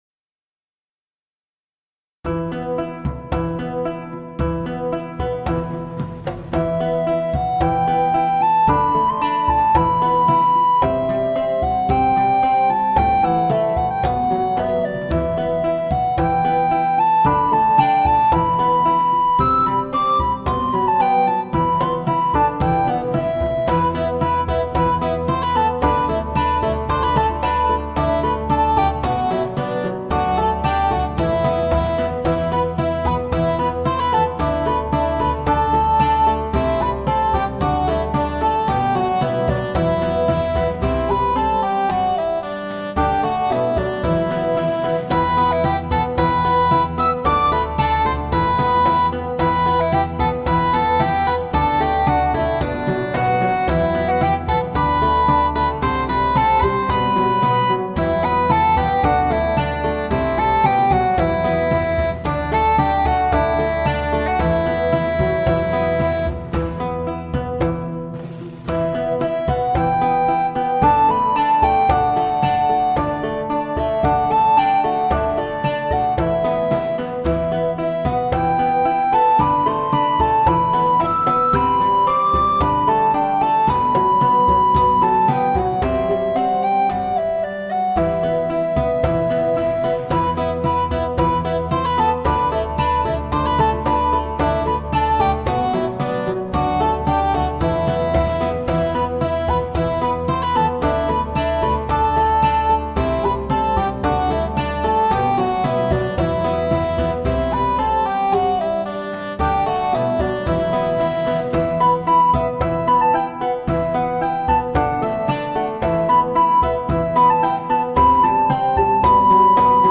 medieval_musique2.mp3